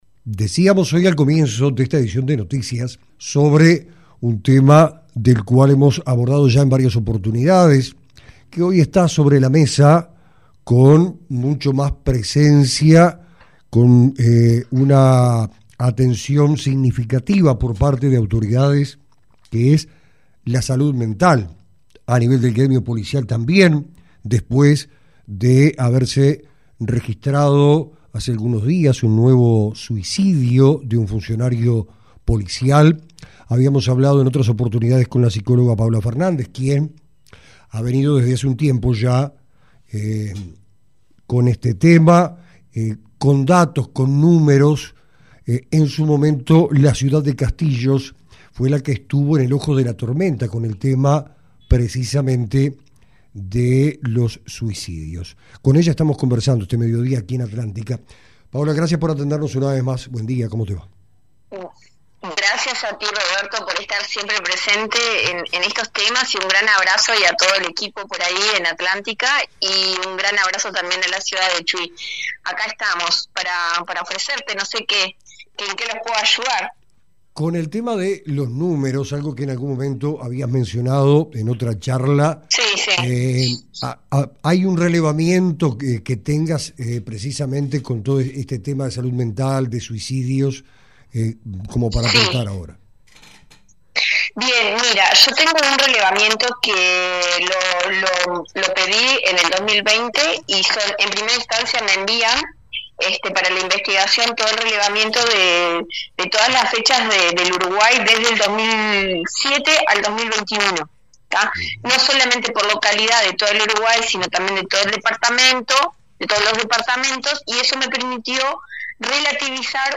La salud mental vuelve a posicionarse como una prioridad urgente en el departamento de Rocha, en diálogo con Atlántica FM